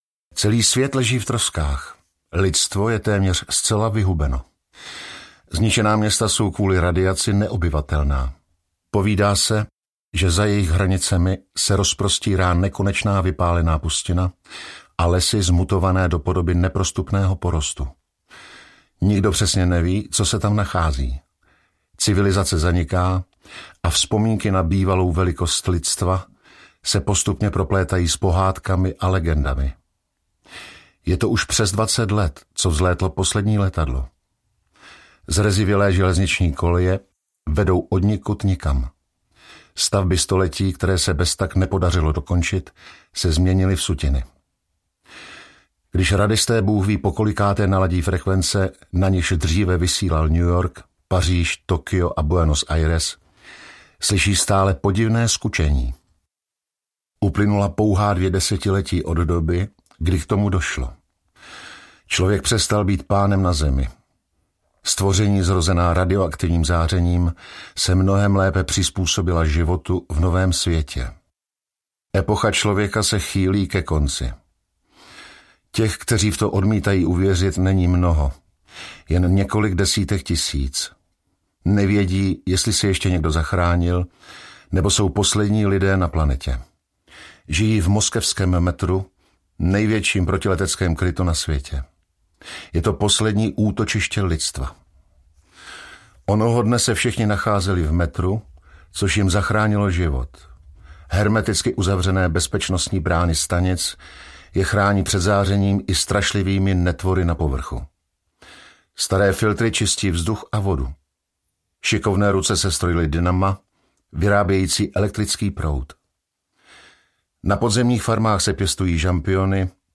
Metro 2034 audiokniha
Ukázka z knihy